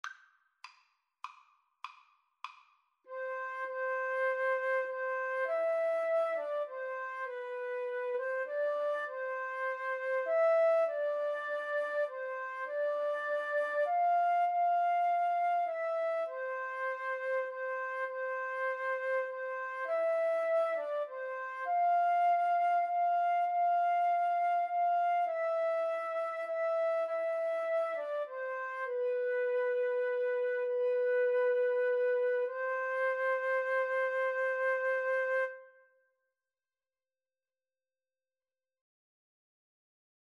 6/4 (View more 6/4 Music)